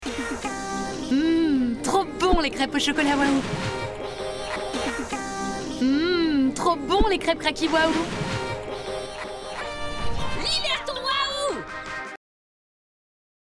Pub TV - Crêpes Whaou - voix ado
Je suis appréciée dans la Pub, les films institutionnels, le jeu vidéo, la narration et ma voix est parfois douce, parfois dynamique et chaleureuse.
5 - 40 ans - Mezzo-soprano